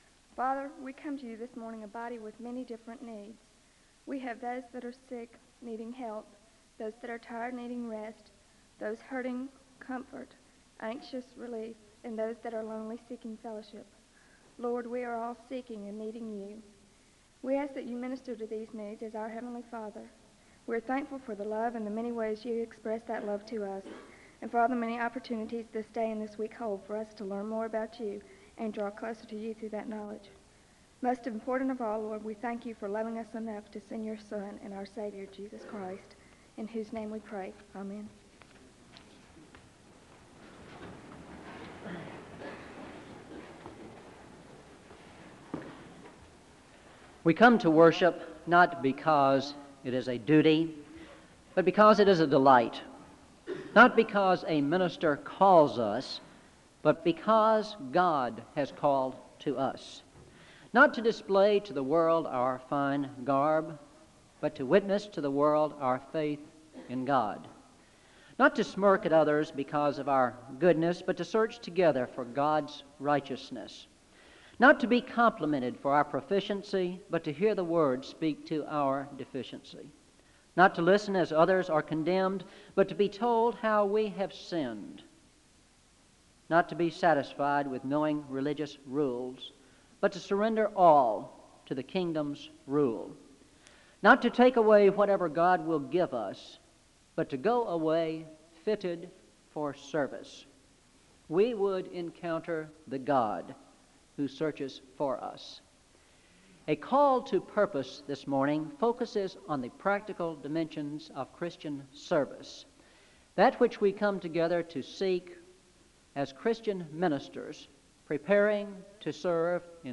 Chapel opens in prayer (0:00-0:38). The Spring Conference is introduced and speakers and visitors on campus for the conference are introduced (0:38-5:55). Scripture is read from Galatians 3:28 and 1 Corinthians 12:12-13 (5:55-7:02). Undecipherable music (7:02-9:32).
Chapel is closed in prayer (19:21-20:17).